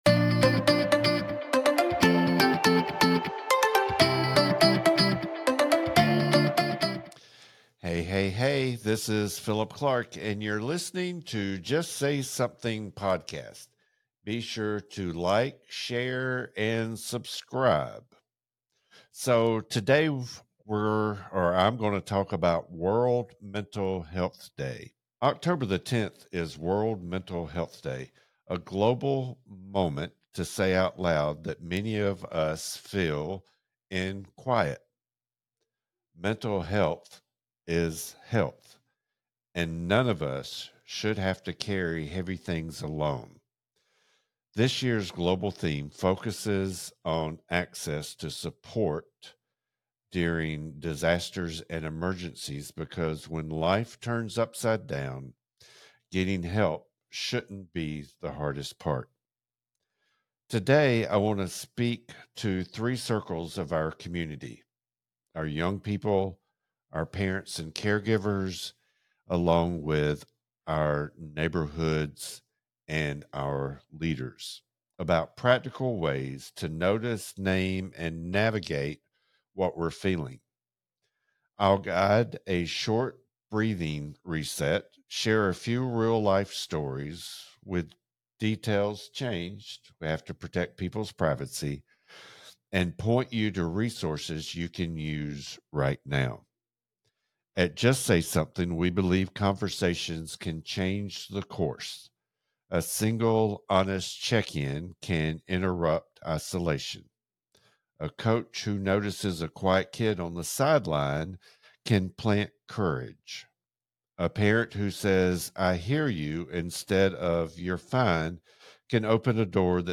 He leads a short breathing exercise, offers simple ways to start honest conversations, and reminds listeners that you are not alone and you are not a burden.